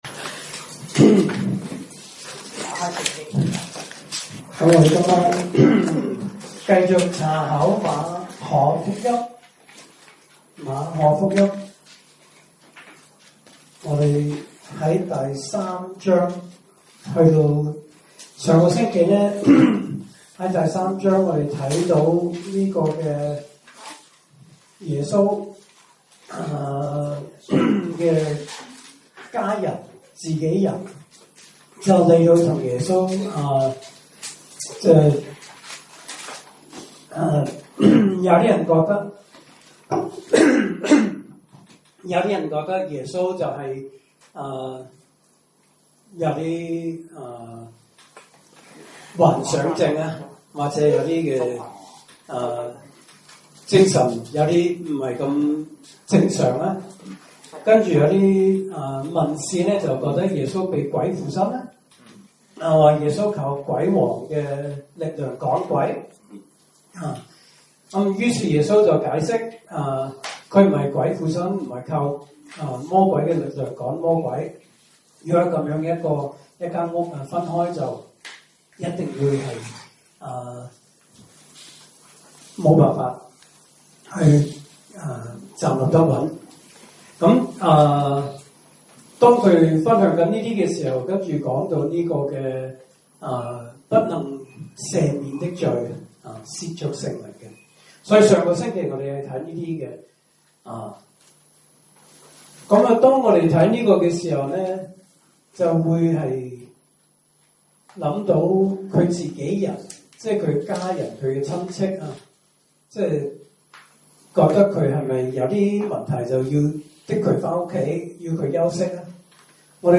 來自講道系列 "查經班：馬可福音"